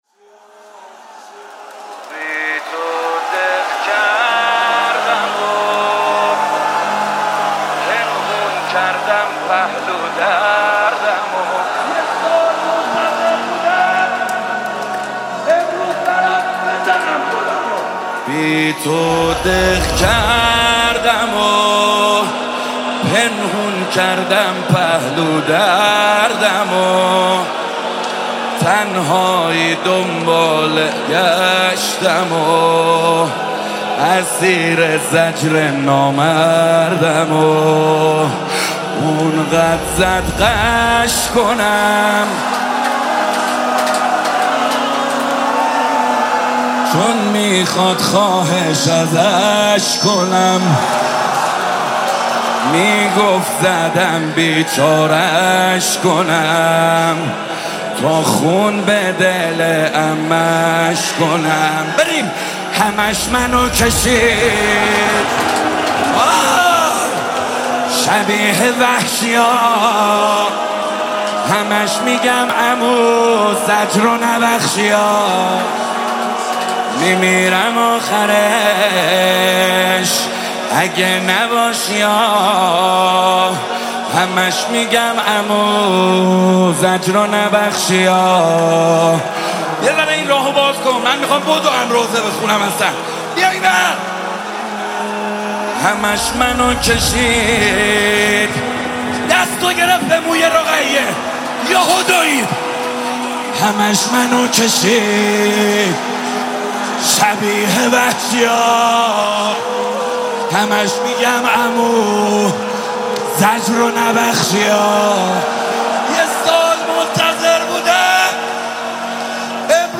با نوای دلنشین
به مناسبت شهادت حضرت رقیه سلام الله علیها